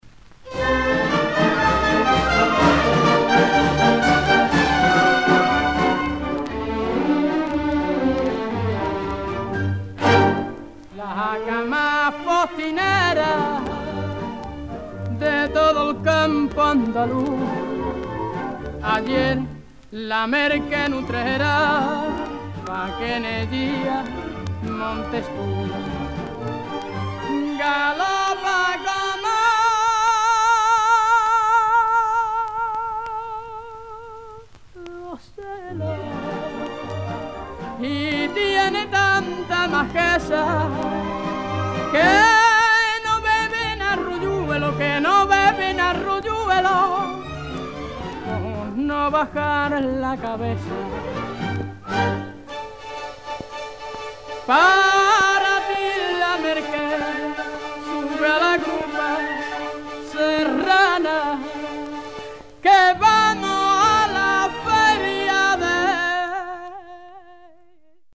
スペインのフラメンコシンガーのレアなキューバ盤!!!